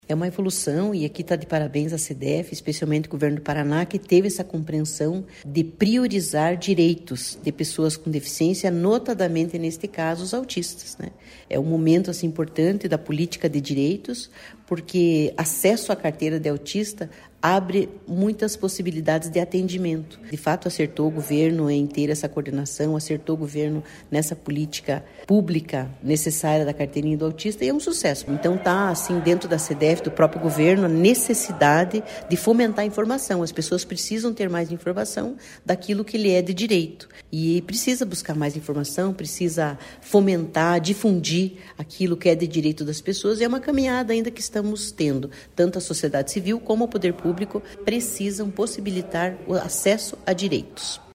Sonora da secretária em exercício do Desenvolvimento Social e Família, Luiza Simonelli, sobre o salto na emissão de Carteiras de Identificação da Pessoa com Transtorno do Espectro Autista em 2023